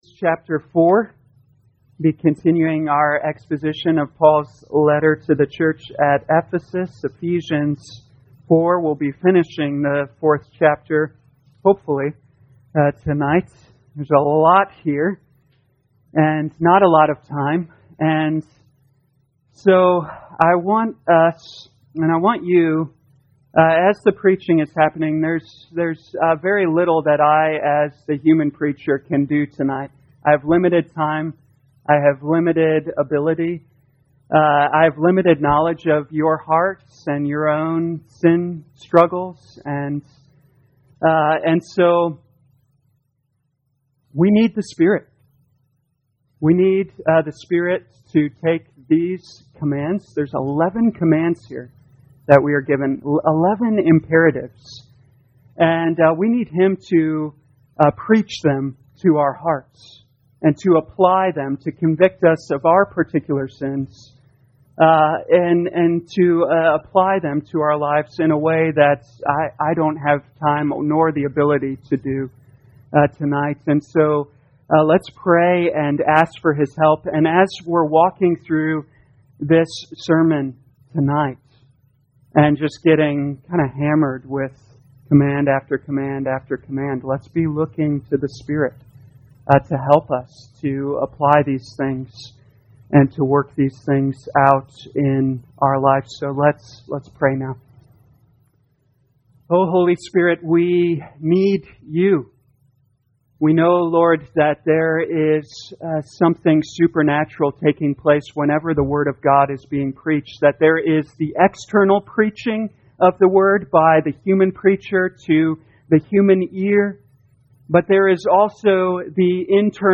2021 Ephesians Evening Service Download